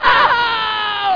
AAAH!.mp3